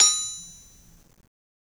Anvil_Hit1_v1_Sum.wav